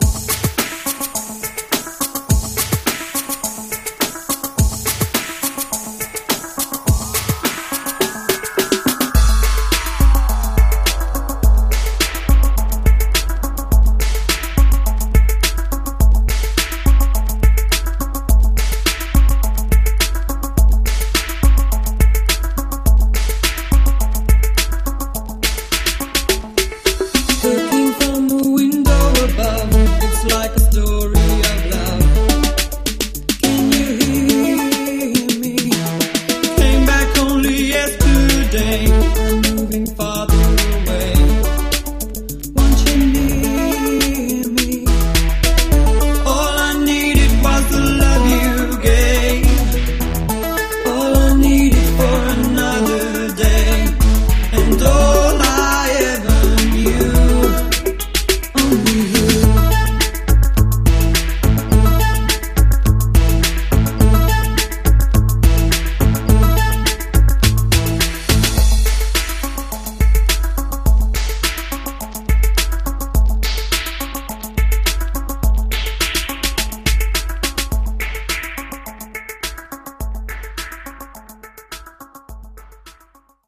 105 bpm
Clean Version